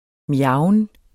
Udtale [ ˈmjɑwən ]